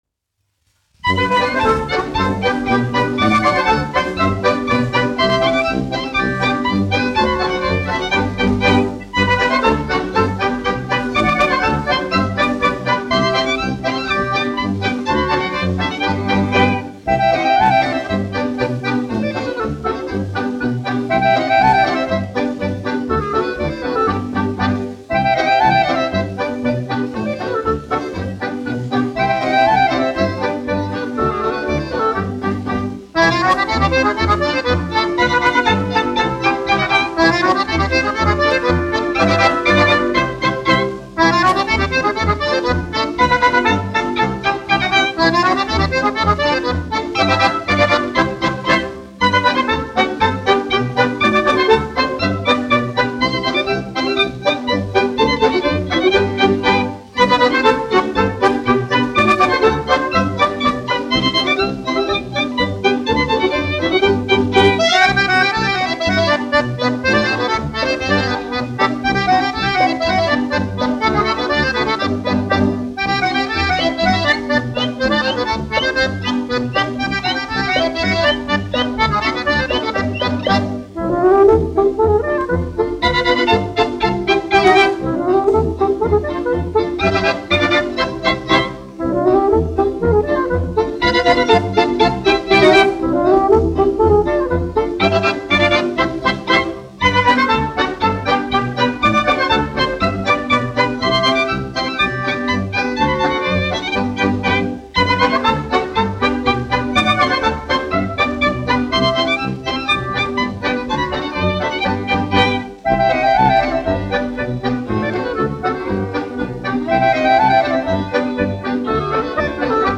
1 skpl. : analogs, 78 apgr/min, mono ; 25 cm
Polkas
Skaņuplate